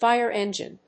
/faɪɚ-ɛn.dʒin(米国英語), faɪə(ɹ)-ɛn.dʒin(英国英語)/
アクセントfíre èngine